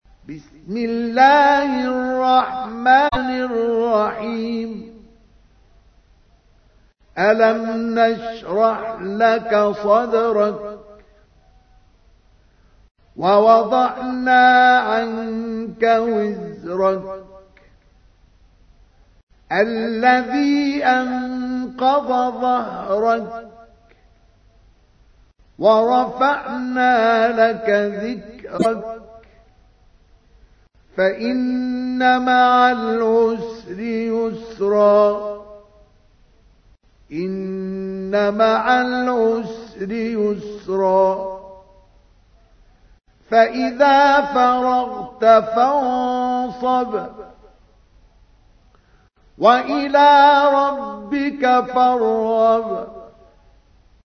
تحميل : 94. سورة الشرح / القارئ مصطفى اسماعيل / القرآن الكريم / موقع يا حسين